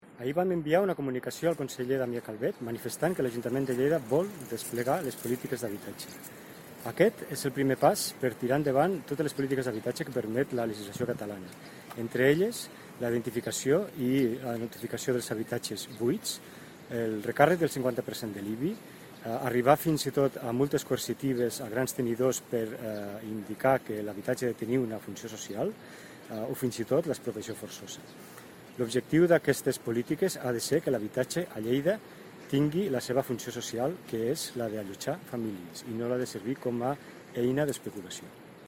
Tall de veu de l'alcalde accidental, Sergi Talamonte, sobre la comunicació al conseller de Territori i Sostenibilitat en què la Paeria manifesta la voluntat d'exercir competències en matèria d'habitatge (604.5 KB)